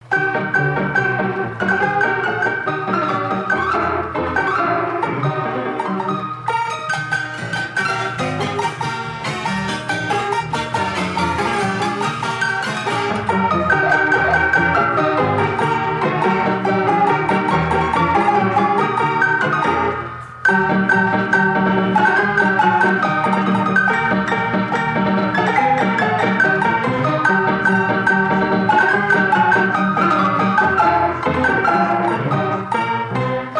THE HONKY TONK PLAYER BARROOM PIANO